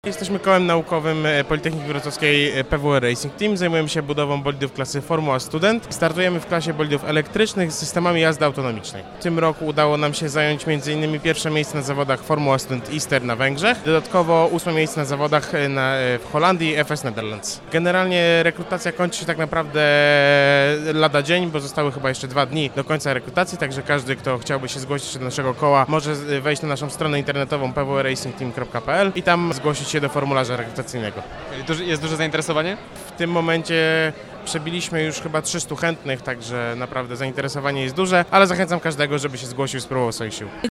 O działalności koła i o rekrutacji mówi jeden z jego członków.